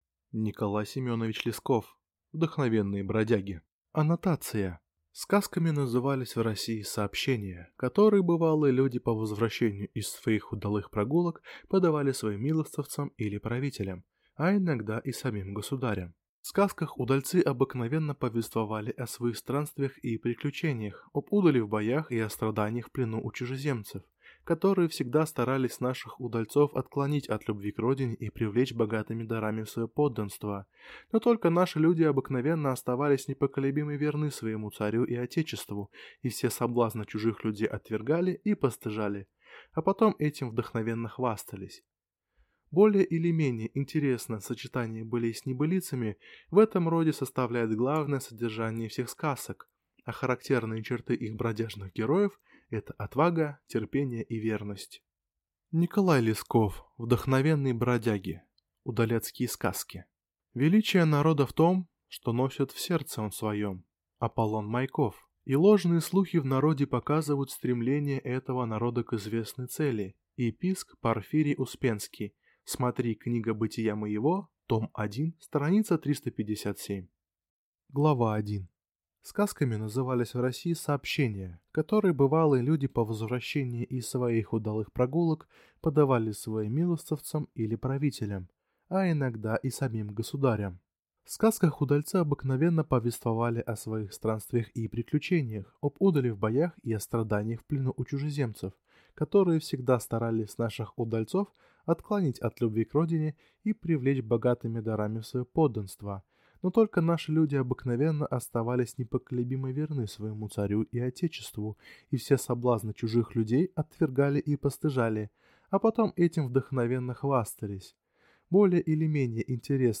Аудиокнига Вдохновенные бродяги | Библиотека аудиокниг